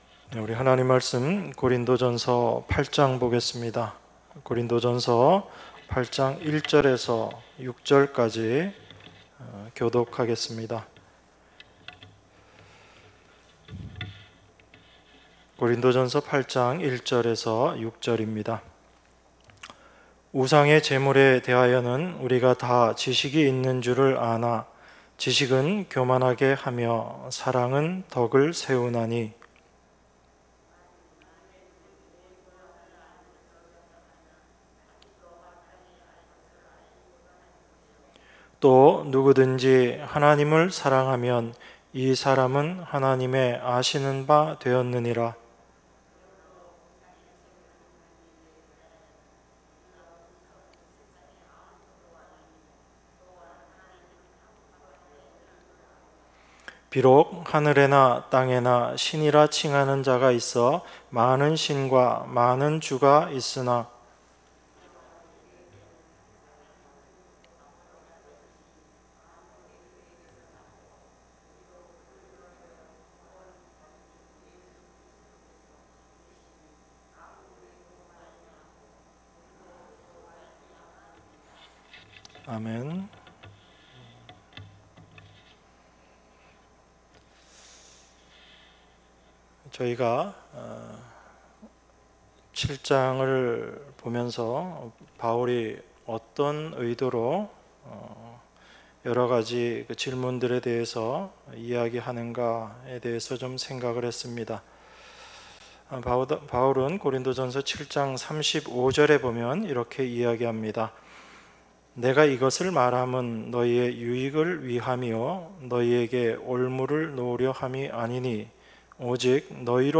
주일1부